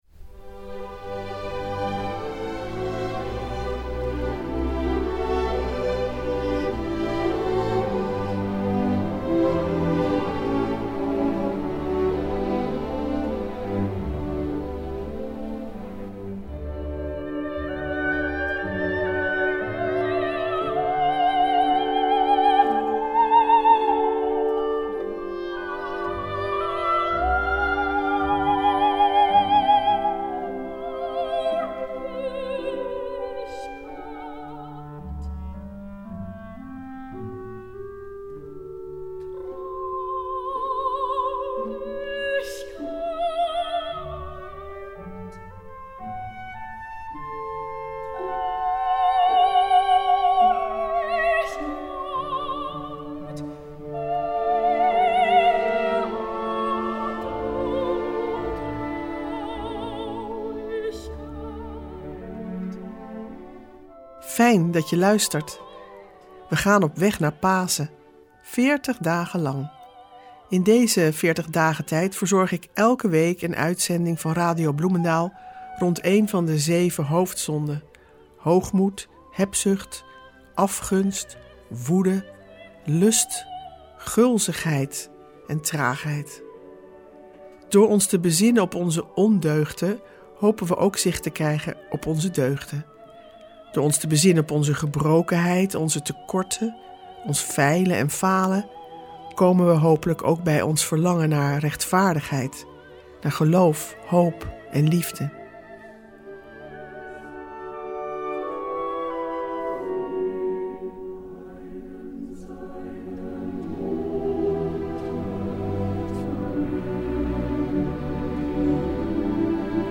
In de komende veertigdagentijd zendt Radio Bloemendaal zeven programma’s uit rond de zeven hoofdzonden: hoogmoed, hebzucht, afgunst, woede, wellust, gulzigheid en gemakzucht.